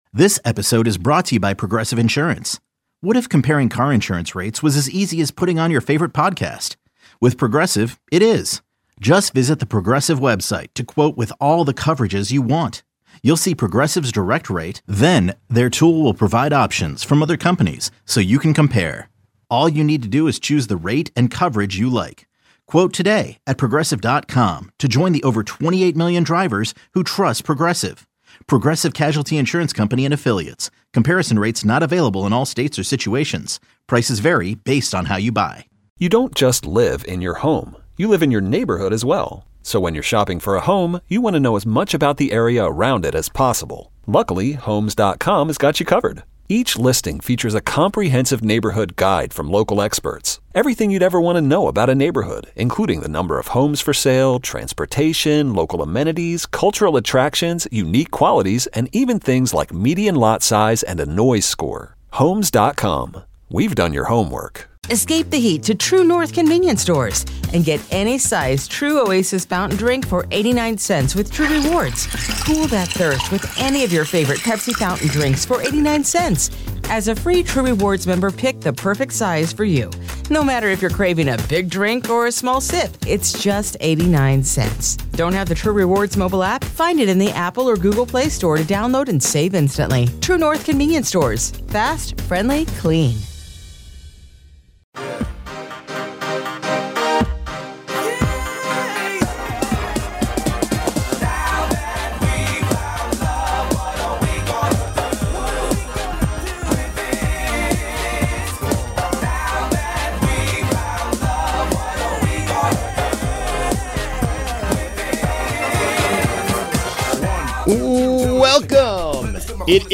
Joined in the second segment by Lieutenant Governor Kathy Hochul. What should reopening look like, what are your concerns.